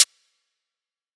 Dreiip Snare.wav